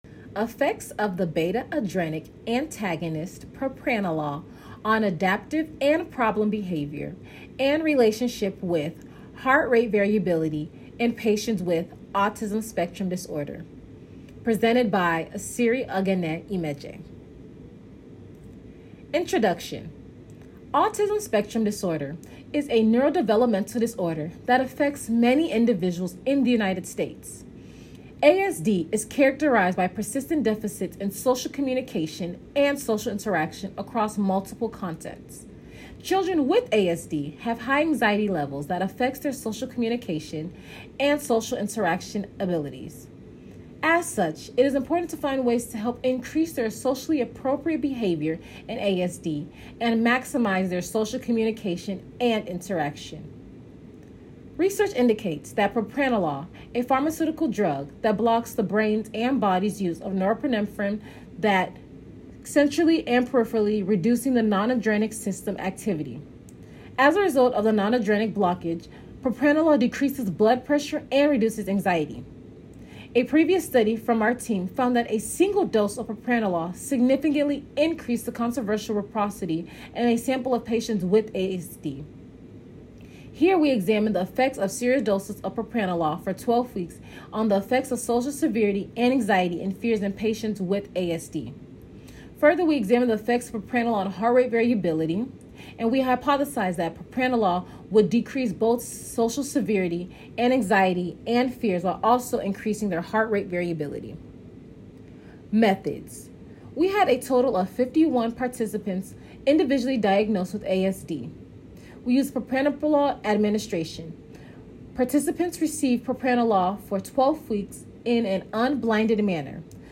Conference papers and proceedings